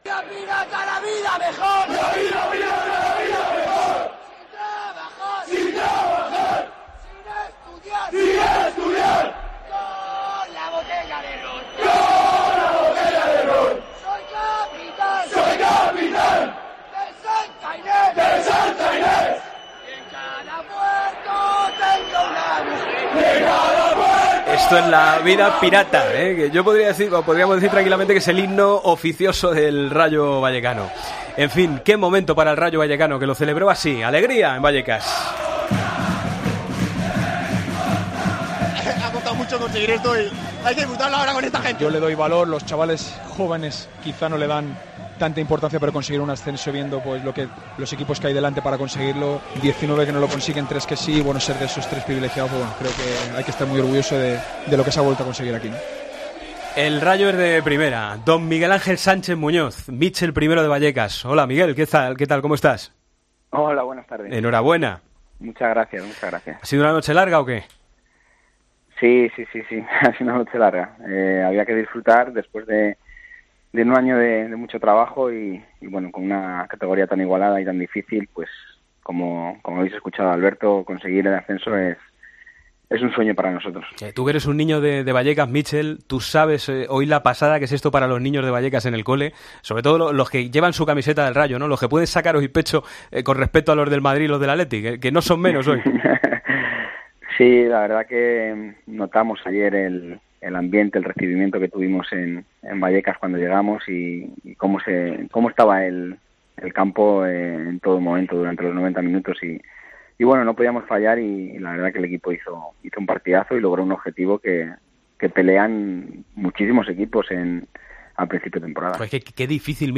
Hablamos con el entrenador del Rayo, un día después del ascenso del equipo de Vallecas: "Conseguir este ascenso es un sueño.